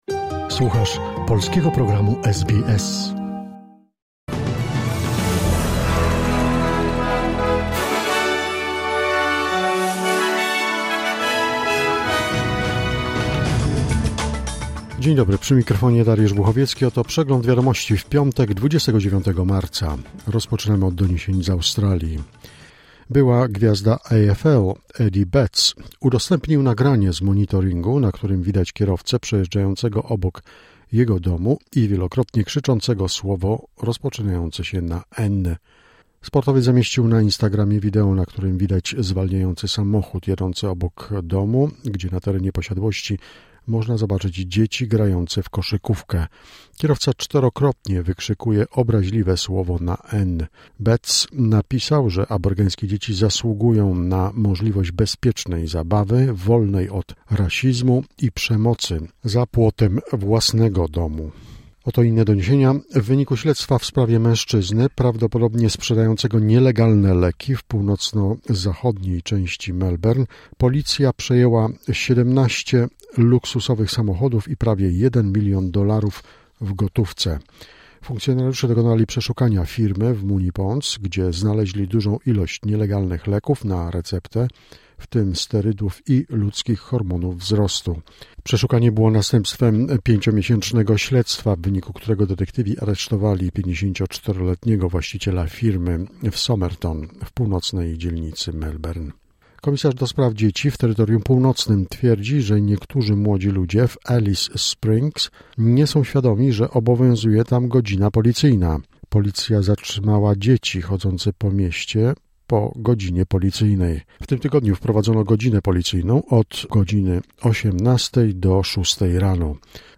Wiadomości 29 marca SBS News Flash